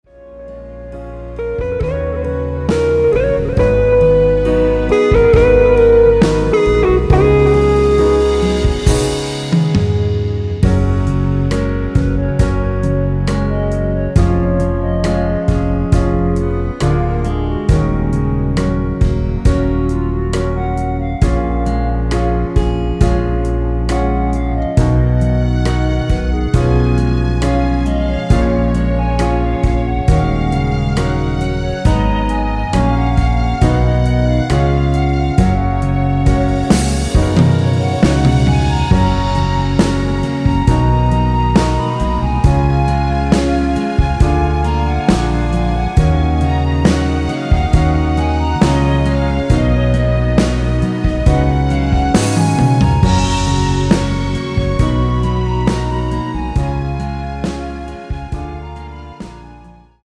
원키에서(-2)내린 멜로디 포함된 MR입니다.(미리듣기 확인)
F#
앞부분30초, 뒷부분30초씩 편집해서 올려 드리고 있습니다.